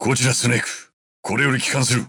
Snake_voice_sample_JP.oga.mp3